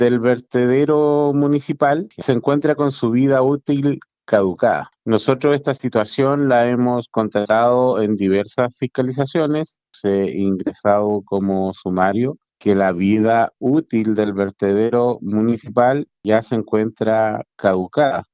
El problema es que existe una orden de cierre en respuesta a lo establecido en el decreto 189 que reglamenta lo referido a las condiciones y de seguridad básica de los rellenos sanitarios, de acuerdo al jefe provincial de la seremi de salud en Osorno, Claudio Millaguin: